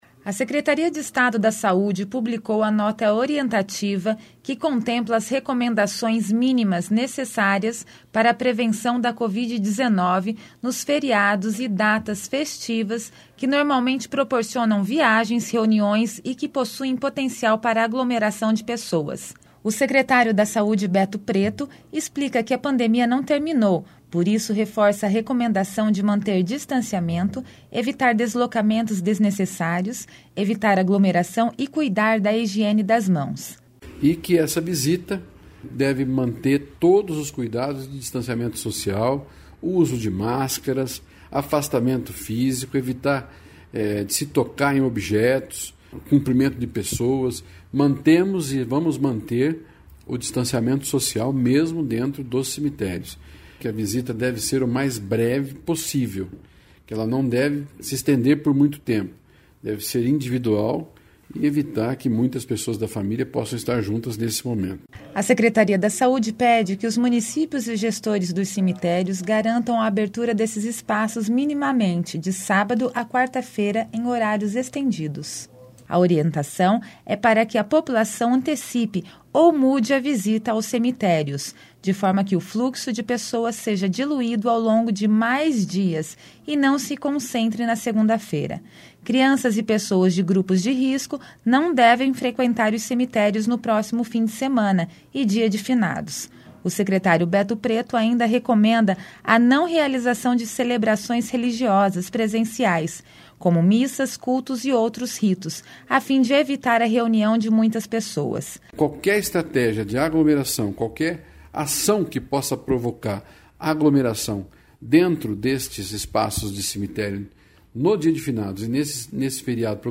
O secretário da Saúde, Beto Preto explica que a pandemia não terminou, por isso, reforça a recomendação de manter distanciamento, evitar deslocamentos desnecessários, evitar aglomeração e cuidar da higiene das mãos.